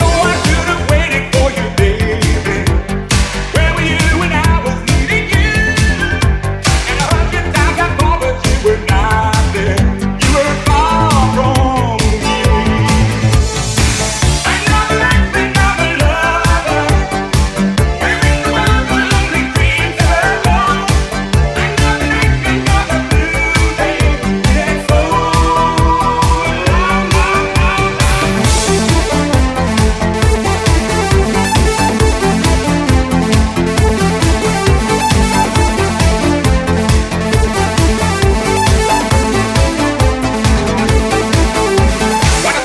Genere: pop, rock, elettronica, successi, anni 80